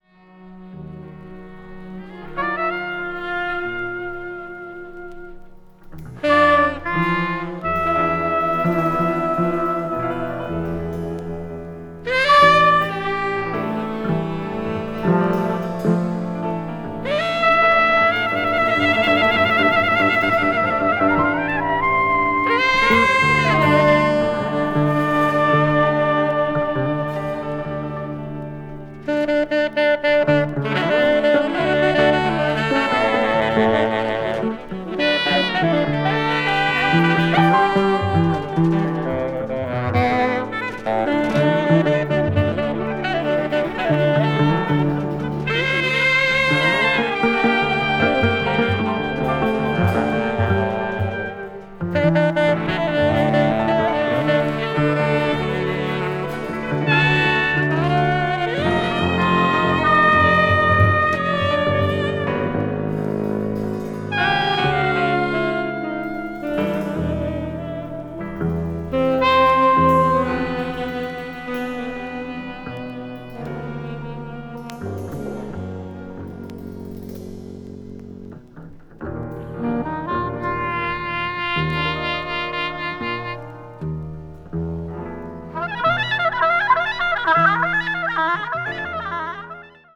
media : EX/EX(わずかにチリノイズが入る箇所あり)
avant-jazz   free jazz   post bop   spiritual jazz